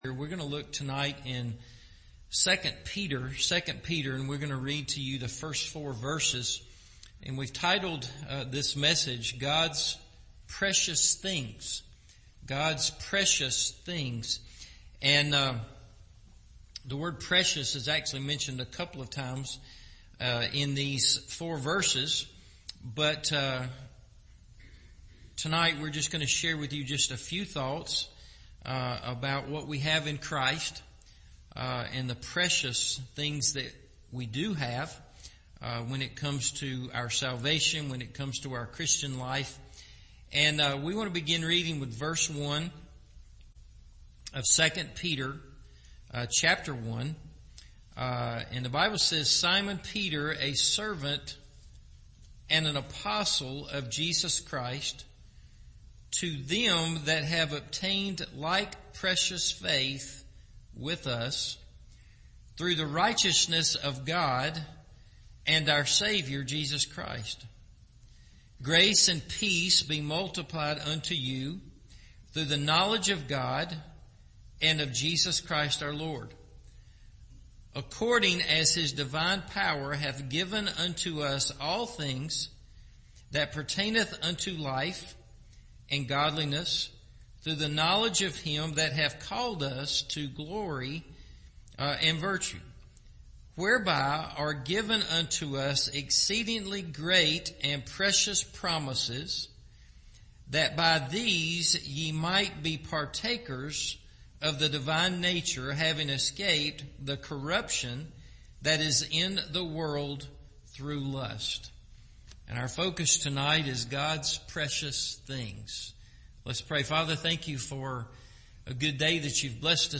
God’s Precious Things – Evening Service